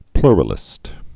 (plrə-lĭst)